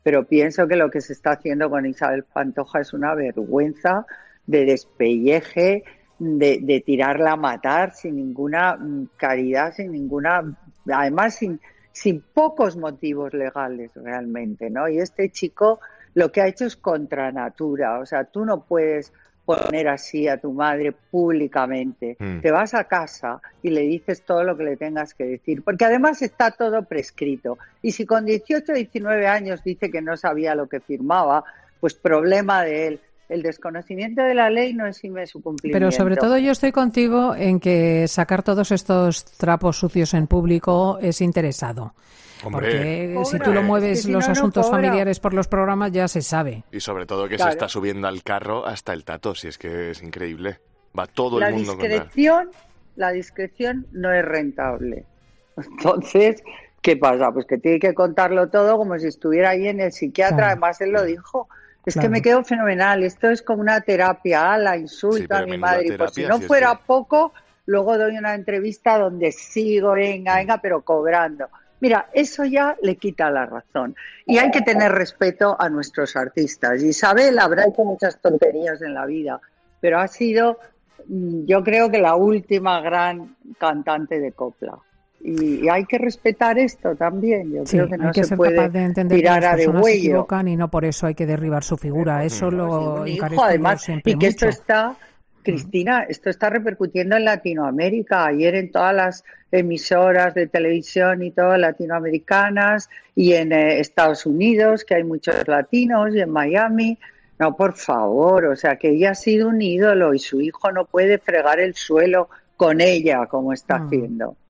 “Pienso que lo que se está haciendo con Isabel Pantoja es una vergüenza, de despelleje, de tirarla a matar sin ninguna caridad, son pocos motivos legales”, comenzaba este sábado la socialité en su sección en Fin de Semana de COPE.